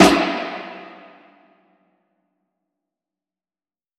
Federhall
Federhall.wav